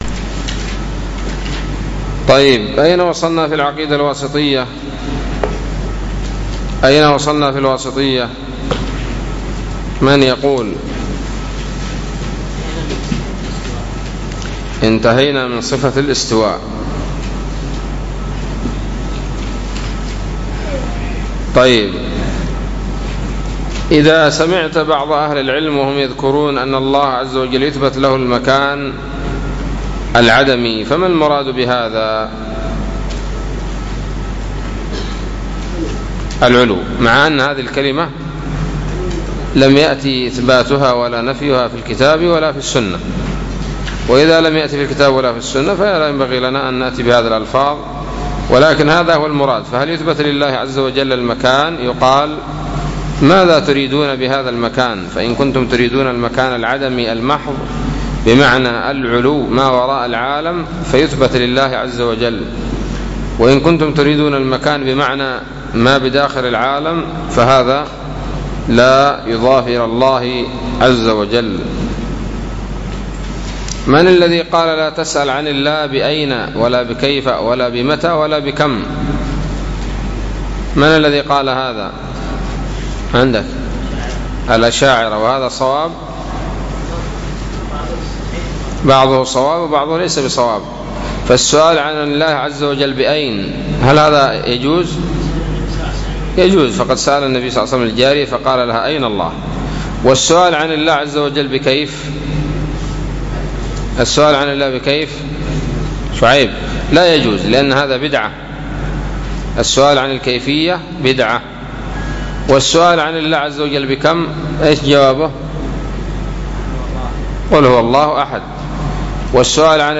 الدرس الخامس والسبعون من شرح العقيدة الواسطية